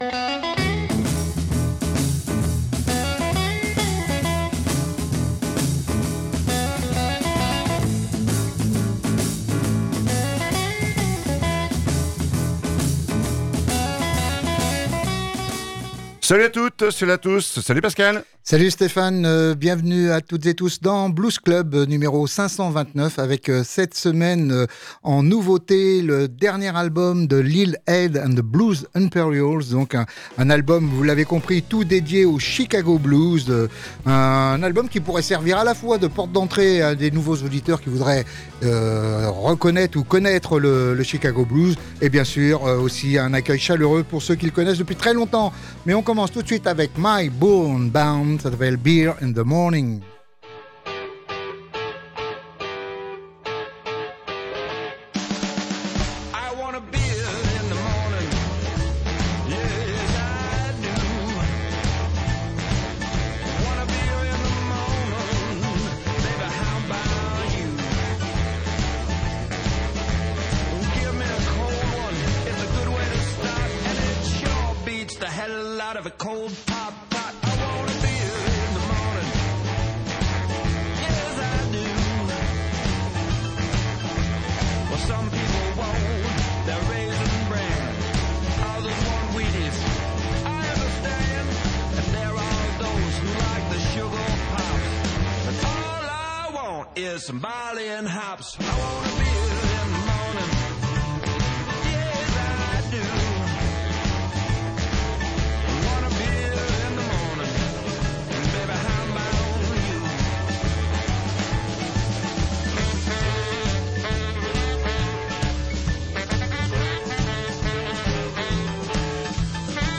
Chicago-blues saignant et sans attendrisseur